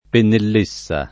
pennellessa [ pennell %SS a ] s. f.